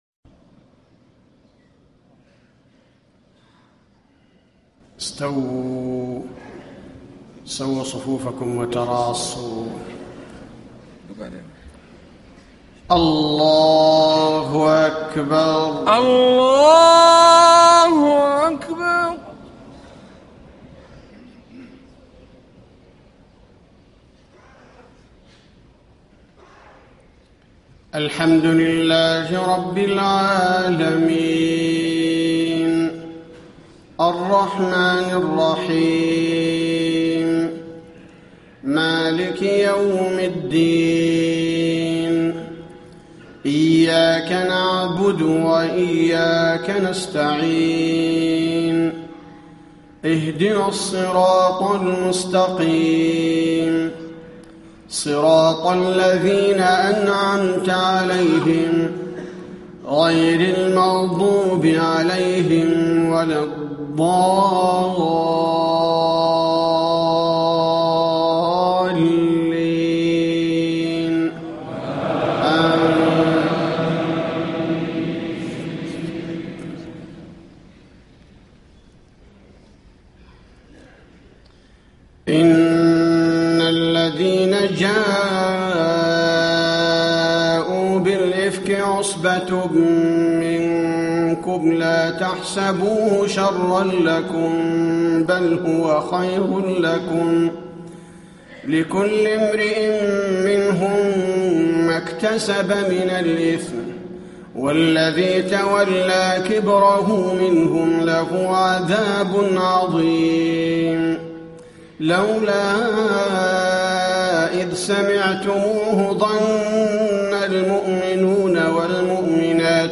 صلاة الفجر 9-5-1434 من سورة النور > 1434 🕌 > الفروض - تلاوات الحرمين